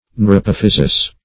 Search Result for " neurapophysis" : The Collaborative International Dictionary of English v.0.48: Neurapophysis \Neu`ra*poph"y*sis\, n.; pl.